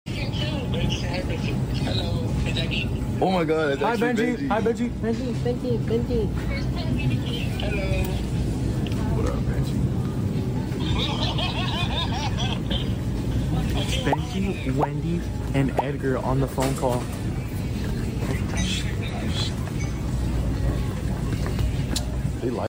He made his voice deep sound effects free download